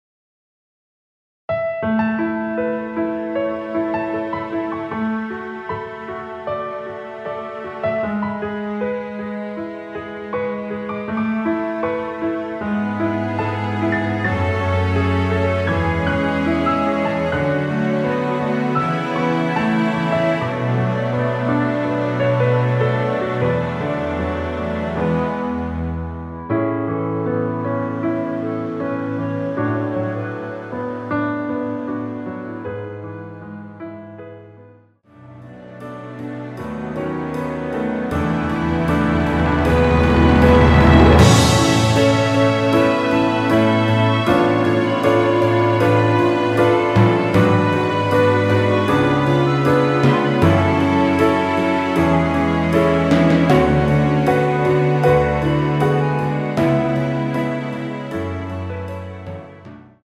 원키 멜로디 포함된 MR입니다.
앞부분30초, 뒷부분30초씩 편집해서 올려 드리고 있습니다.
(멜로디 MR)은 가이드 멜로디가 포함된 MR 입니다.